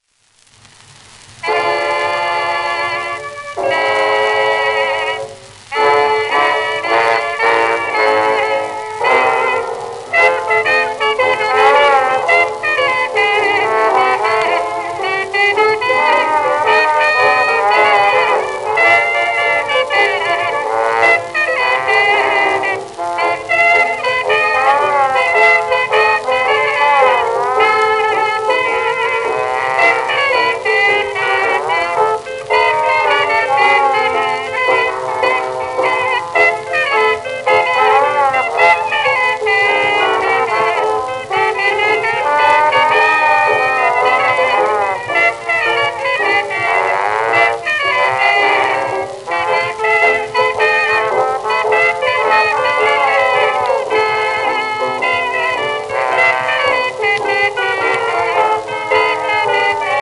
1923年録音
旧 旧吹込みの略、電気録音以前の機械式録音盤（ラッパ吹込み）